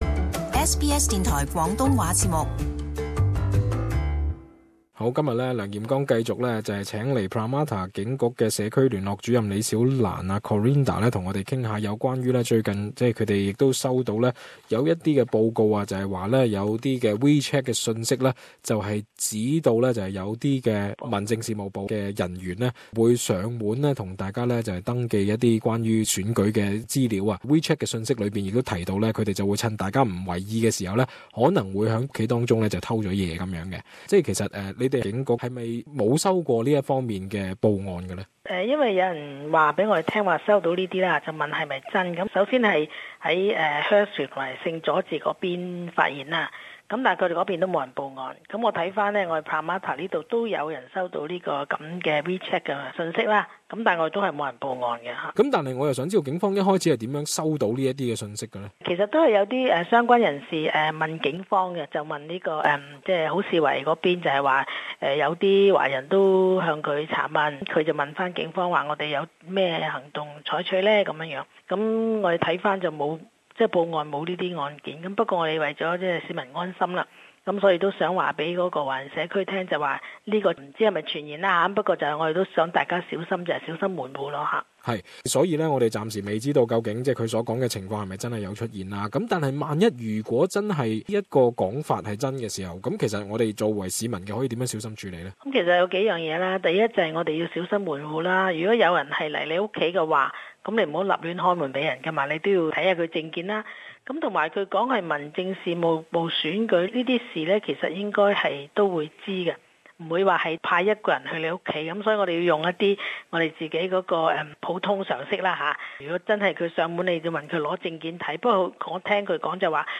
【社區專訪】新州警方呼籲市民小心處理Wechat傳閱訊息